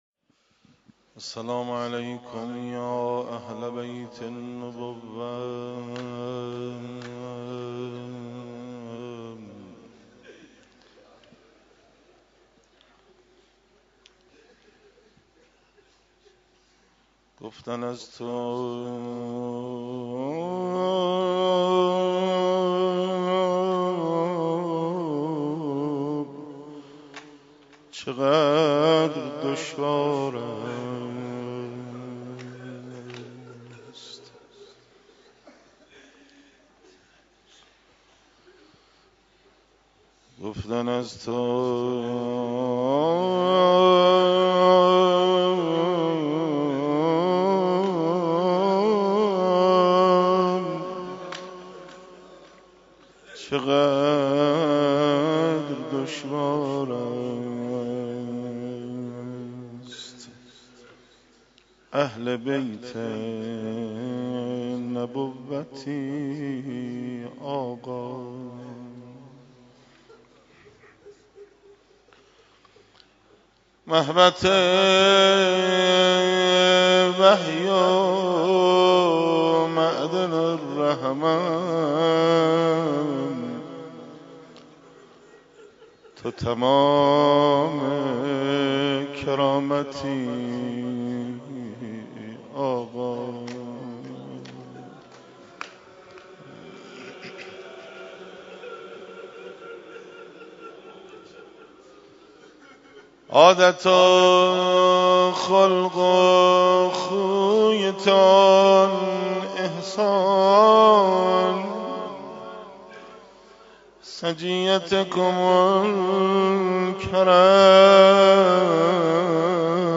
شهادت امام هادی (ع) 94 - روضه - گفتن از تو چقدر دشوار است